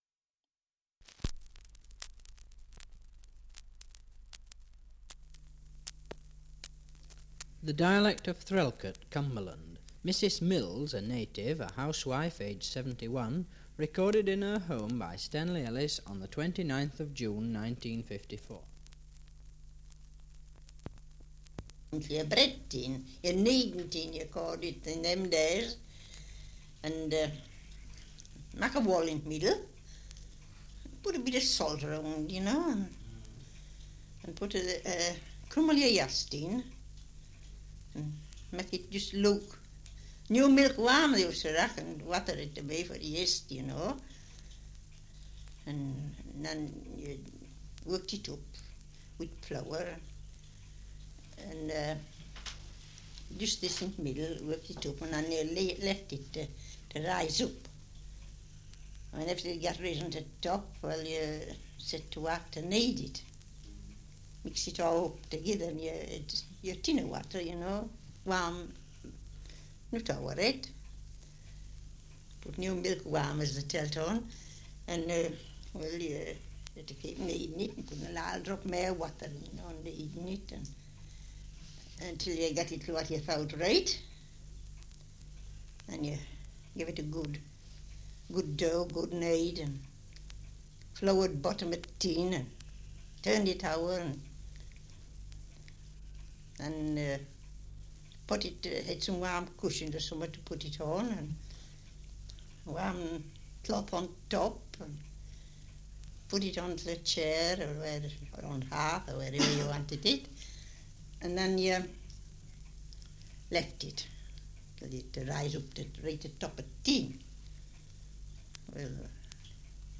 Survey of English Dialects recording in Threlkeld, Cumberland
78 r.p.m., cellulose nitrate on aluminium